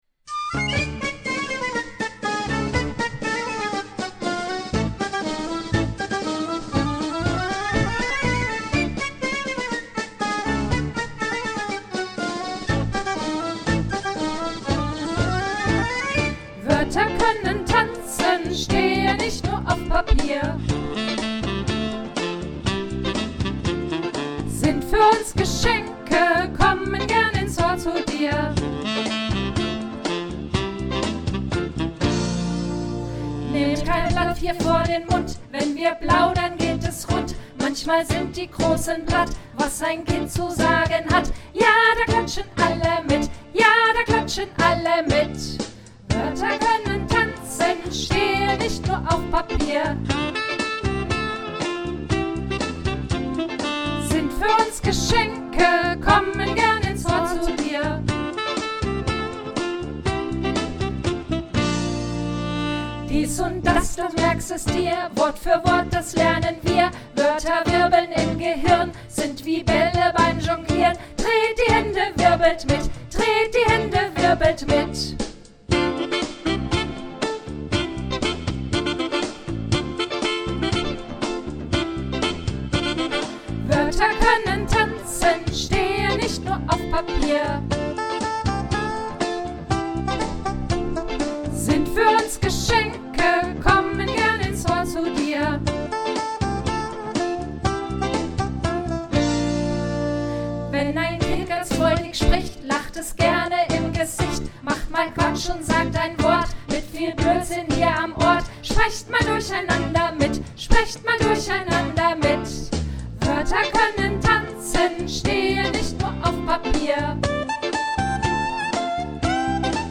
Woerter-koennen-tanzen-gesungen.mp3